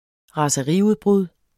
Udtale [ ʁɑsʌˈʁi- ]